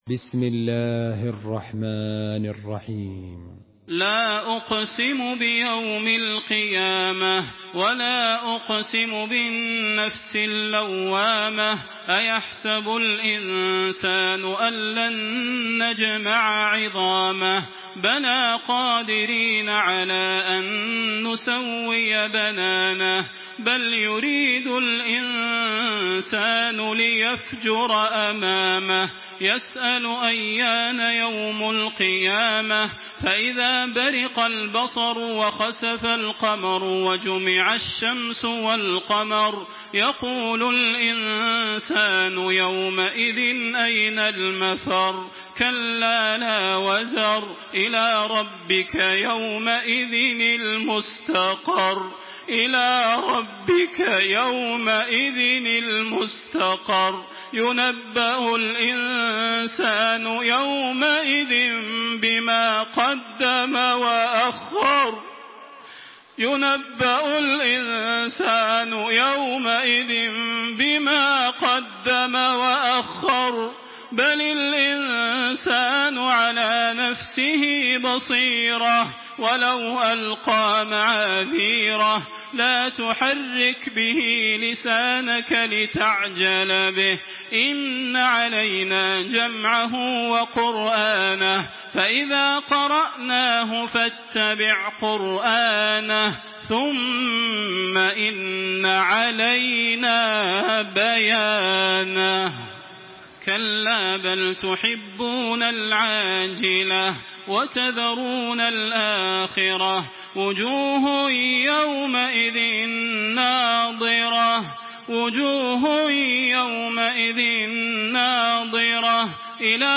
Surah Al-Qiyamah MP3 by Makkah Taraweeh 1426 in Hafs An Asim narration.
Murattal